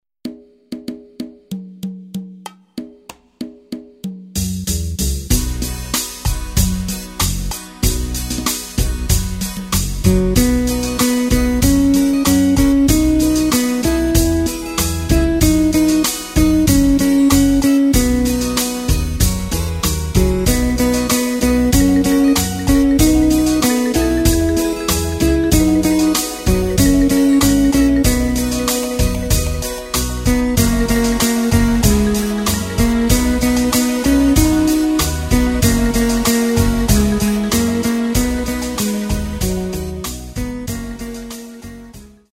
Takt:          4/4
Tempo:         95.00
Tonart:            B
Schlager aus dem Jahr 1965!